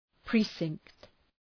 Προφορά
{‘pri:sıŋkt}